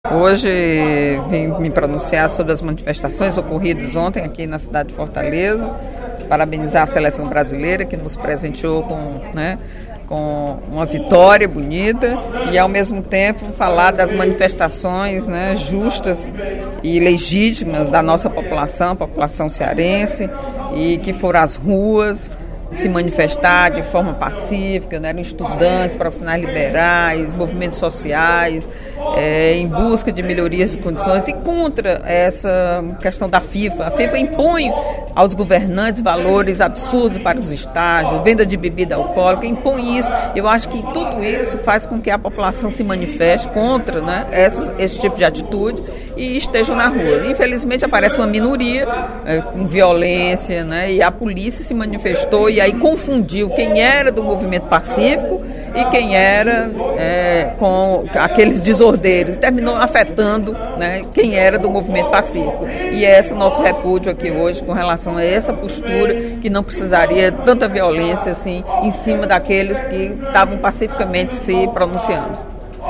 Durante o primeiro expediente da sessão plenária desta quinta-feira (20/06), a deputada Eliane Novais (PSB) criticou as cenas “lamentáveis” de violência nas manifestações de ontem (19) por melhorias nos serviços públicos no Estado.